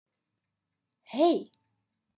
Download Hey sound effect for free.